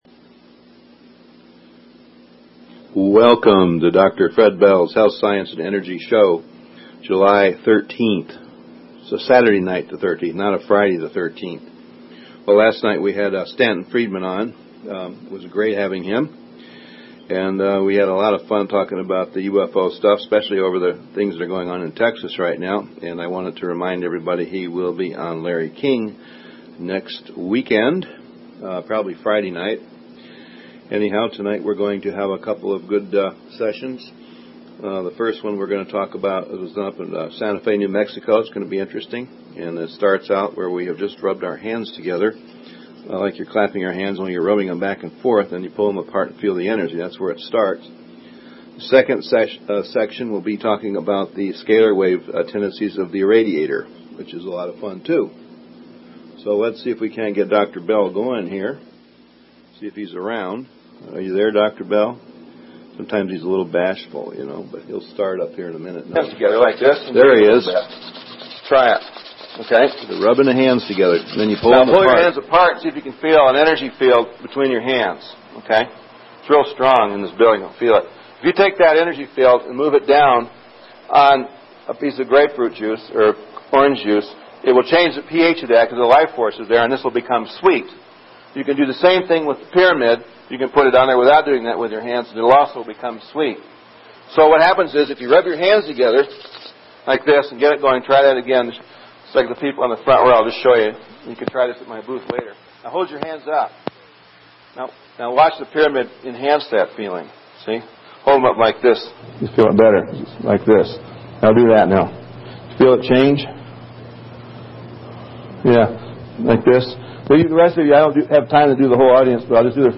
Talk Show Episode, Audio Podcast, Dr_Bells_Health_Science_and_Energy_Show and Courtesy of BBS Radio on , show guests , about , categorized as
Tonights was a great flash in the past of some early lectures!